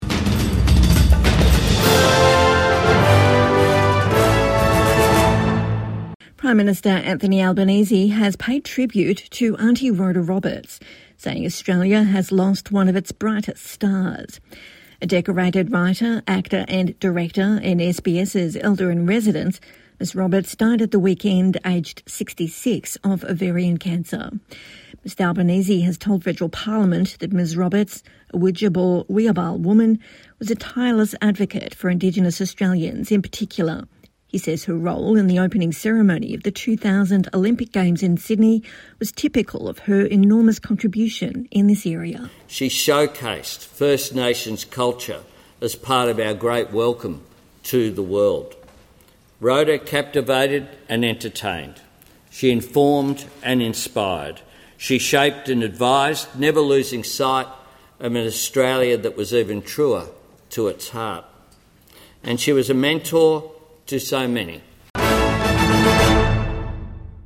Anthony Albanese pays tribute in Parliament to SBS elder in residence, Aunty Rhoda Roberts